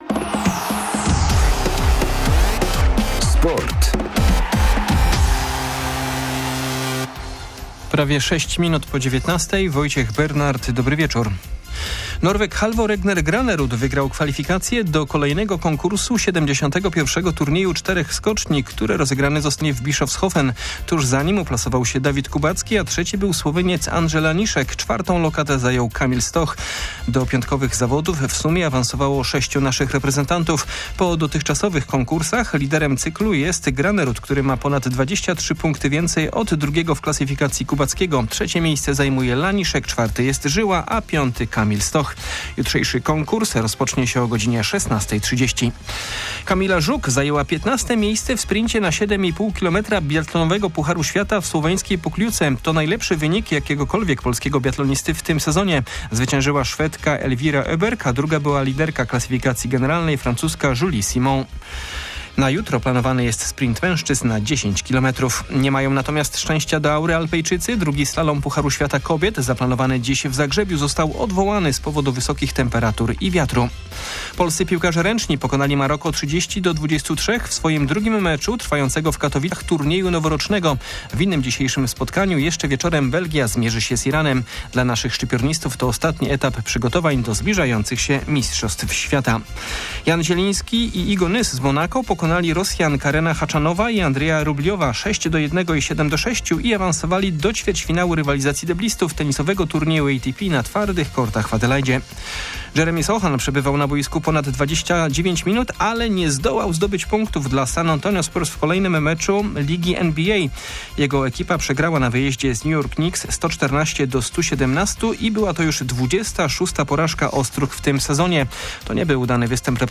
05.01.2023 SERWIS SPORTOWY GODZ. 19:05